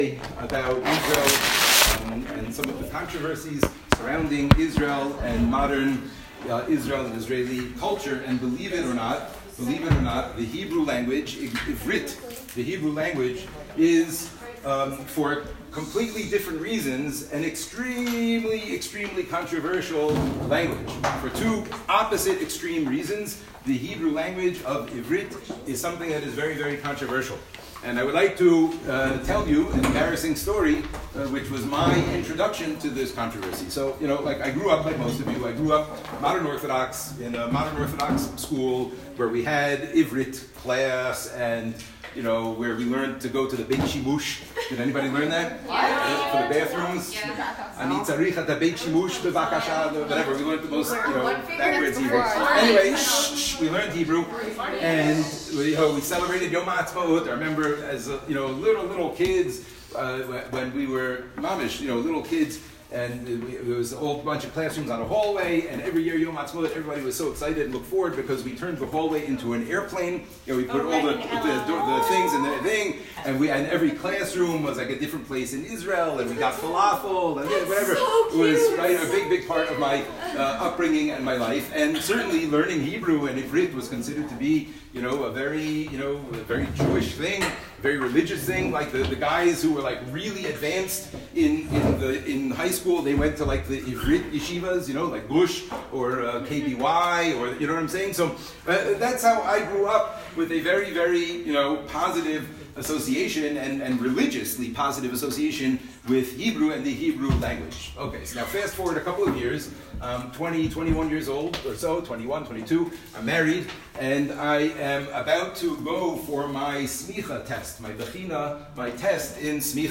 Lecturer